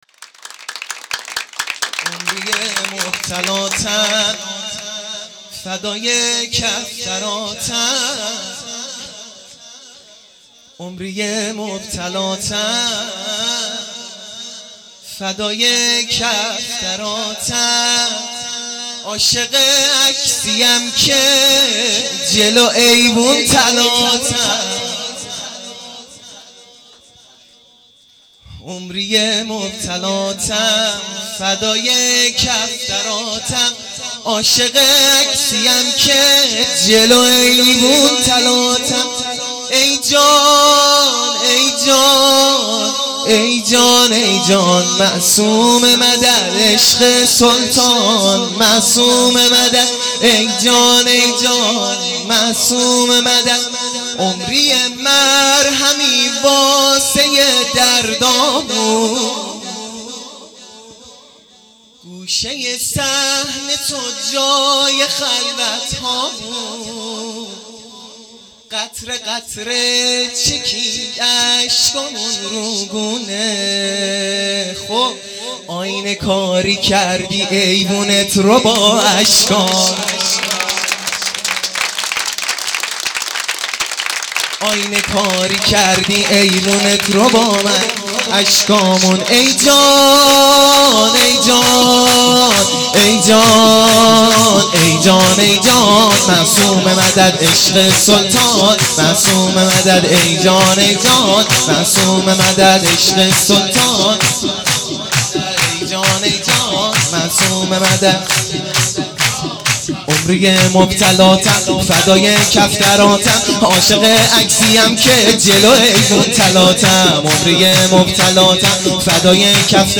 میلاد حضرت معصومه س